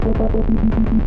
描述：循环的节奏来自Moog rogue和Phototheremin的即兴创作
Tag: 即兴 循环 穆格 光特雷门 样品 空间 老虎